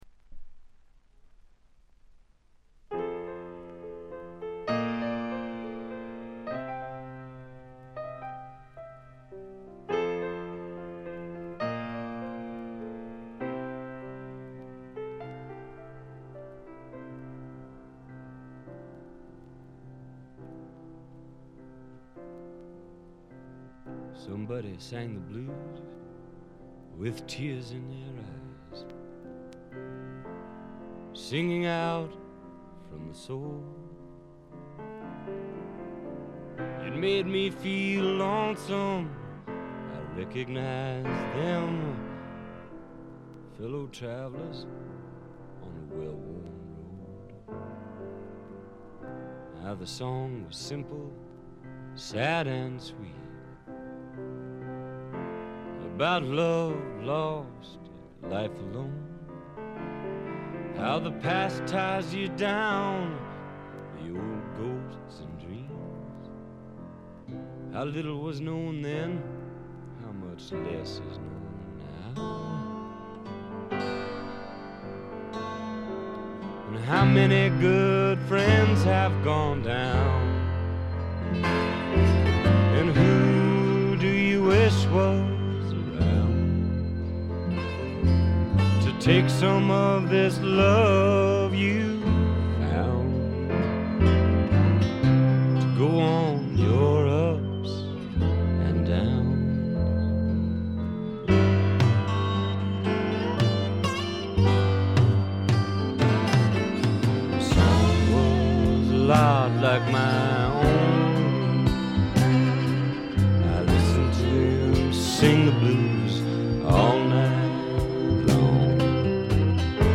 これ以外はわずかなノイズ感のみ。
試聴曲は現品からの取り込み音源です。